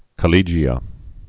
(kə-lējē-ə, -lĕgē-ə)